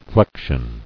[flec·tion]